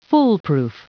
Prononciation du mot foolproof en anglais (fichier audio)
Prononciation du mot : foolproof